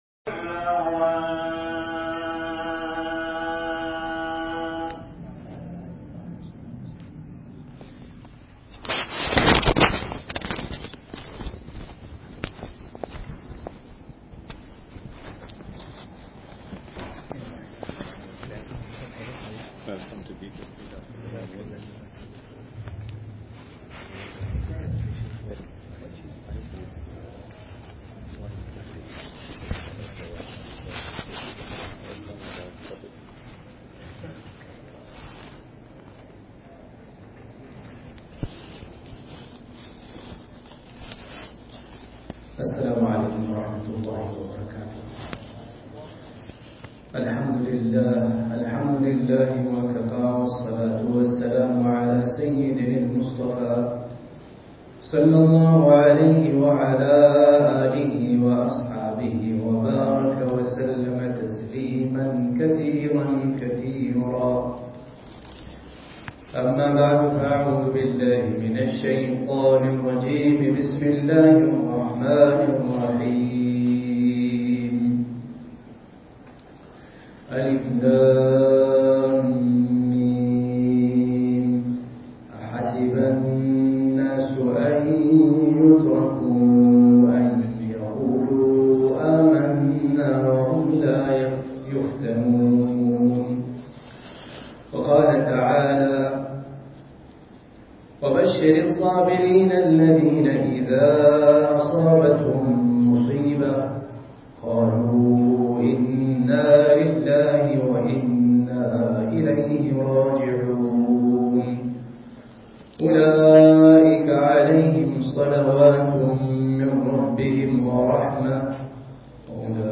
Jumua – Beatrice St. Musalla